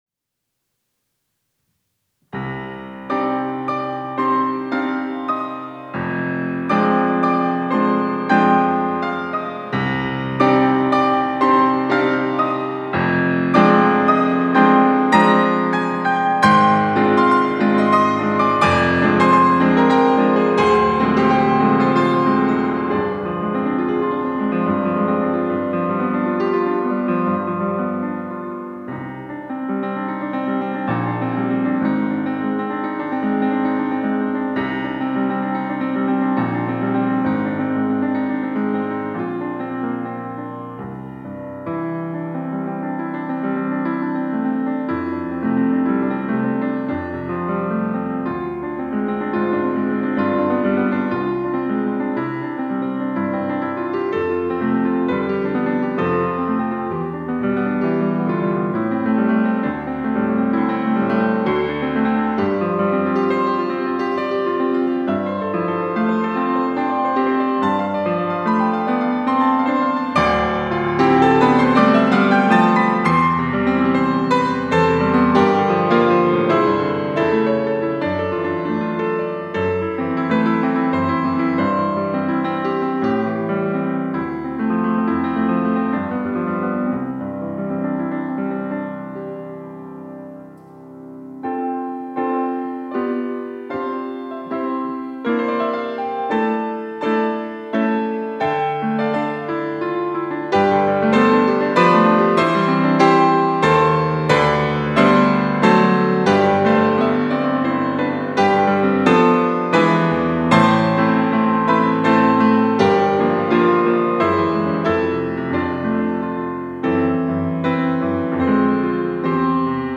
특송과 특주 - 오 신실하신 주